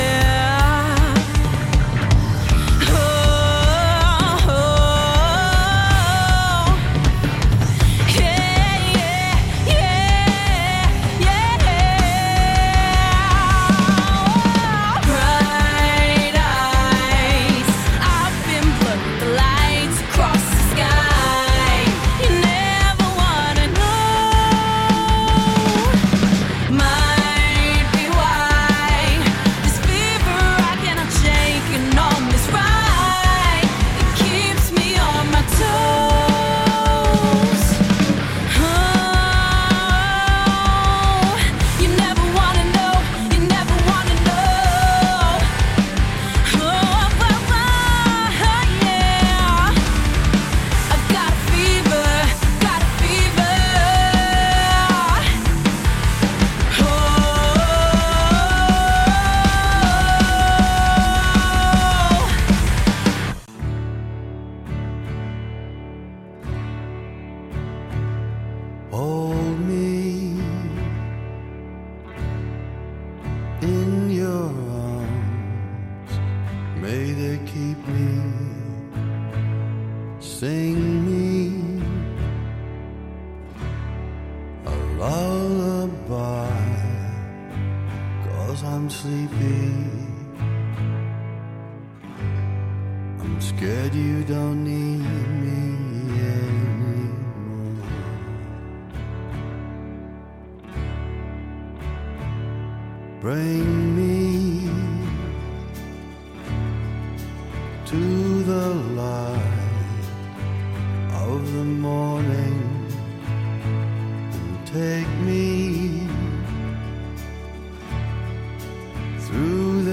A weekly news and reviews show devoted to cinema in and around Sheffield.